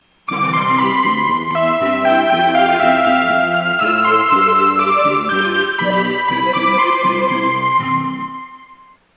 Using from effect sound collection.
Departure merody